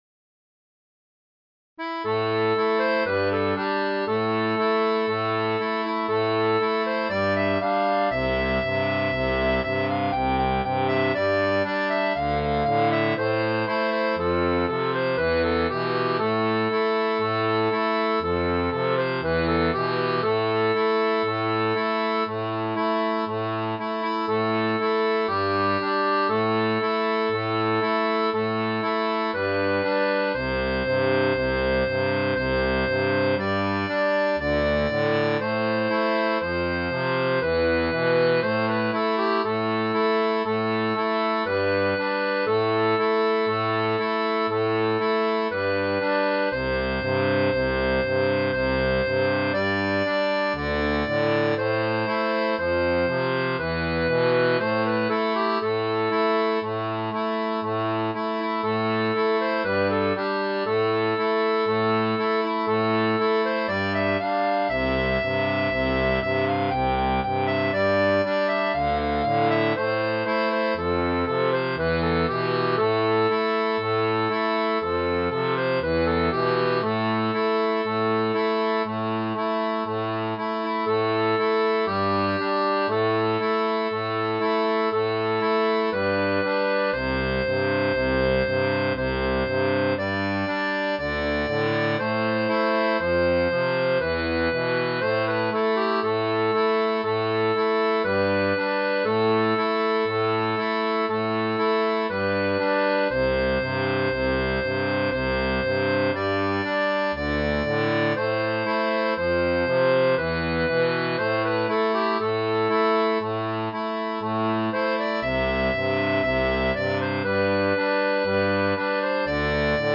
• La tablature pour accordéon diatonique 2 rangs
Folk et Traditionnel